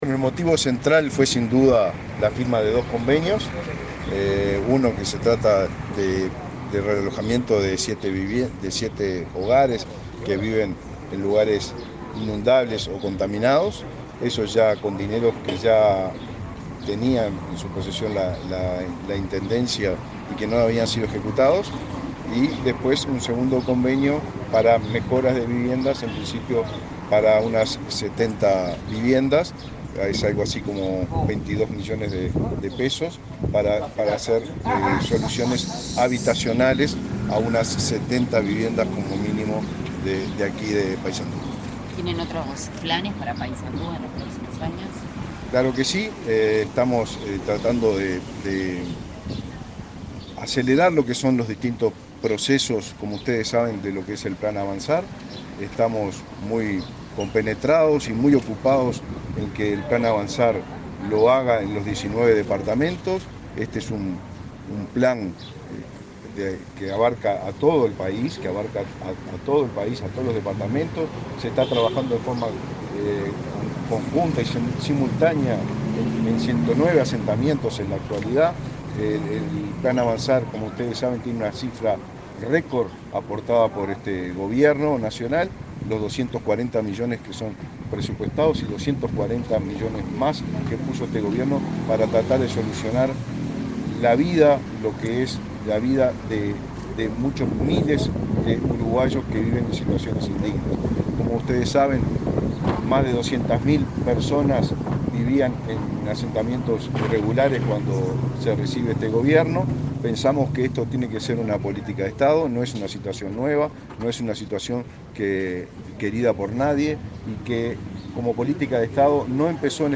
Declaraciones del ministro de Vivienda, Raúl Lozano
El ministro de Vivienda, Raúl Lozano, dialogó con la prensa en Paysandú, luego de firmar dos convenios con el intendente Nicolás Olivera, para